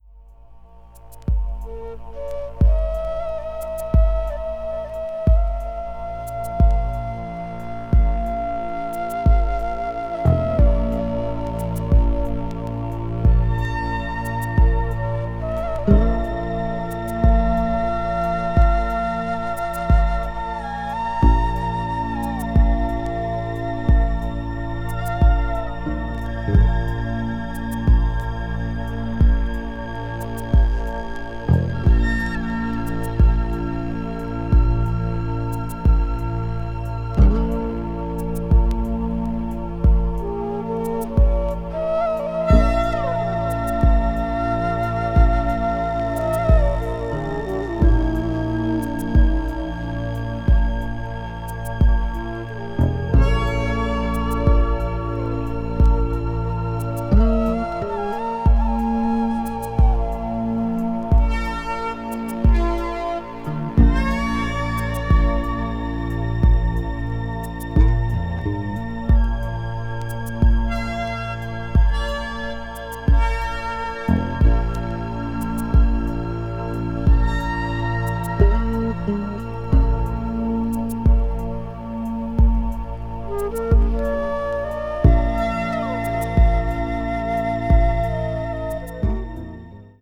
electronic   meditation   new age   oriental   synthesizer